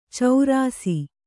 ♪ caurāsi